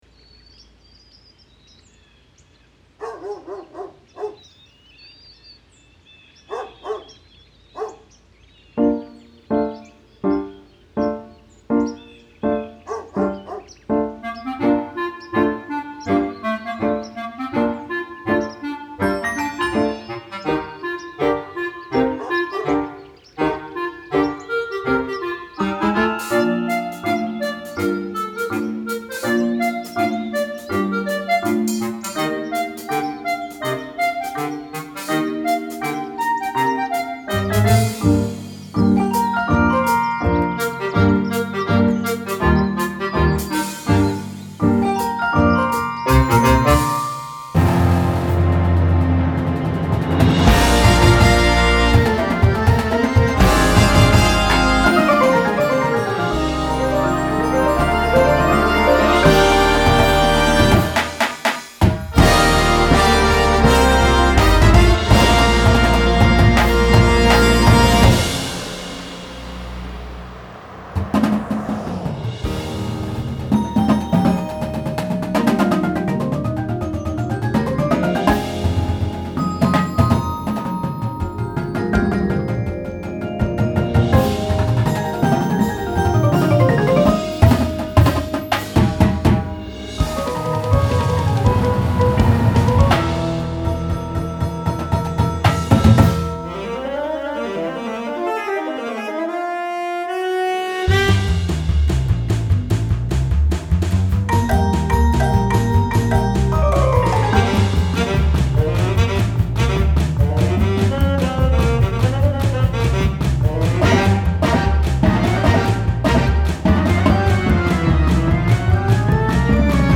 Marching Band Shows
Winds
Percussion